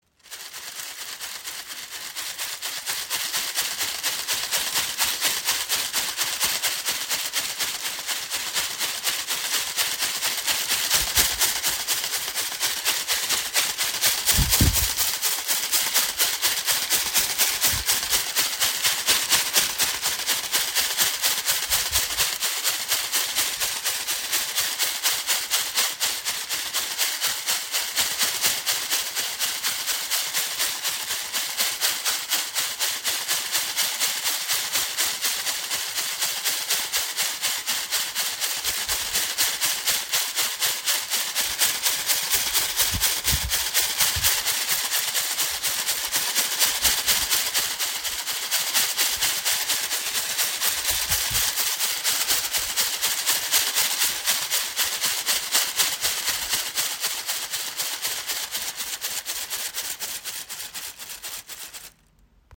Rassel der Shipibo | Chacapa Blätter-Rassel | Perlenarbeit im Raven-Spirit WebShop • Raven Spirit
Klangbeispiel
Da ihr Sound an das Rauschen der mächtigen Baumwipfel des Waldes erinnert und ihre Bewegungen die Luft sanft zirkulieren lässt, tragen sie den „Wind des Waldes“ in sich.